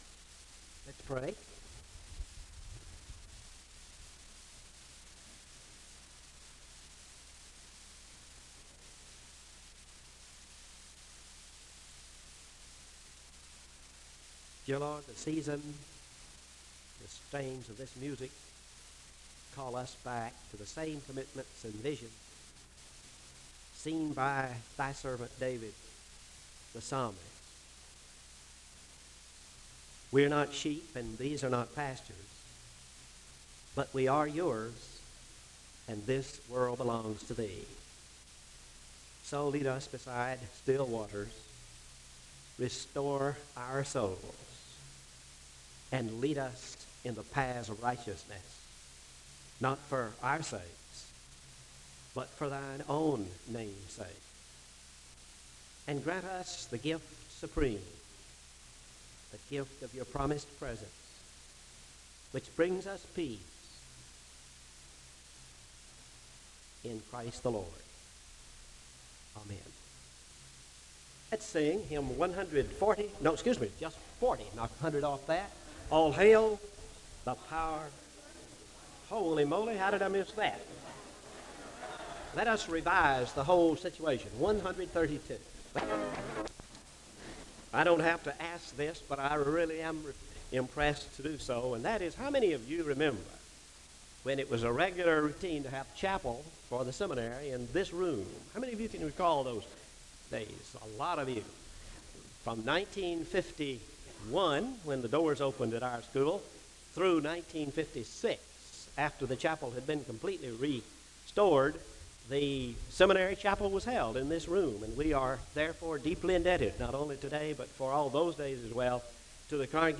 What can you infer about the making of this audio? The service opens with a word of prayer (00:00-01:09).